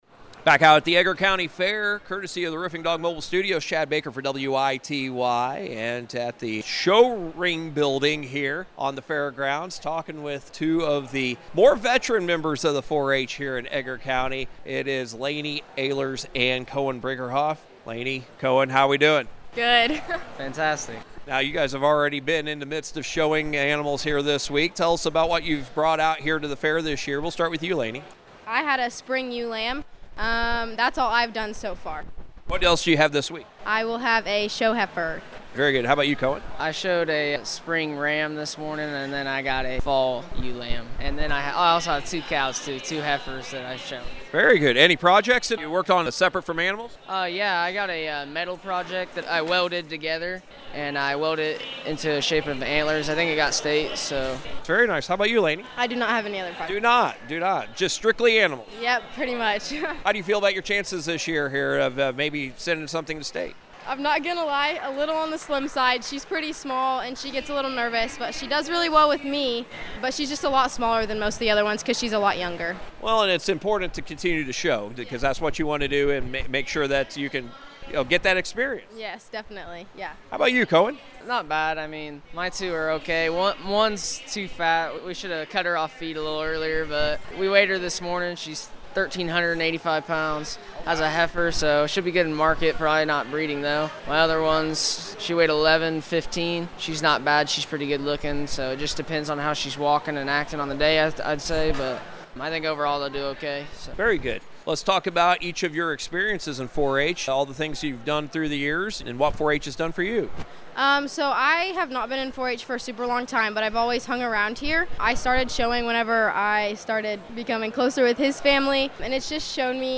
WITY’s coverage of the Edgar County Fair from the Roofing Dog Mobile Studios is presented by Diepholz Auto Group, Precision Conservation Management, Longview Bank, Edgar County Farm Bureau, Ag Prospects, Prospect Bank, Nutrien Ag Solutions in Metcalf, Chrisman Farm Center, and First Farmers Bank & Trust.